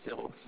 harmonics subtraction sound
Tech. description: 8khz, 16 bit mono adpcm